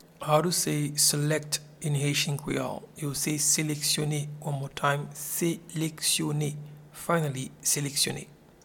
Pronunciation and Transcript:
Select-in-Haitian-Creole-Seleksyone.mp3